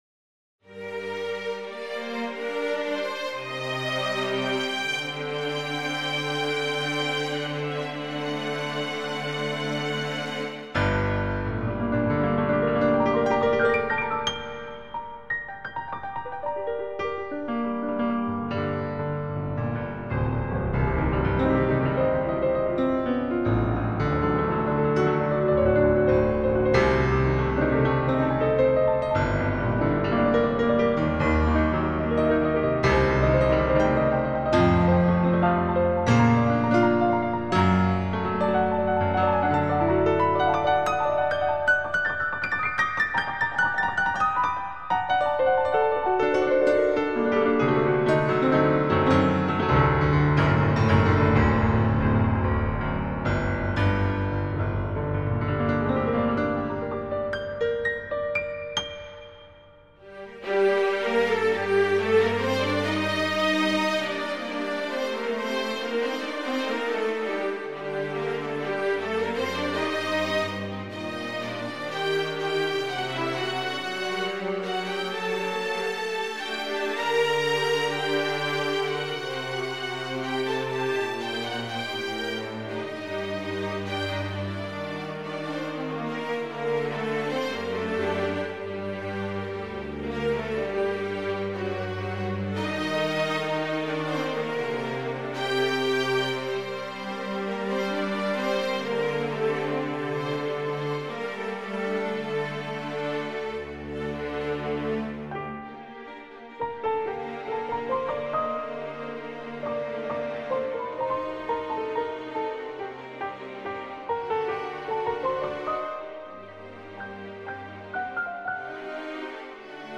Concerto pour Piano n°3 en Sol mineur | Trensistor Webradio
Aujourd’hui, une composition originale : le 3e Concerto pour piano soliste et orchestre, de ma composition.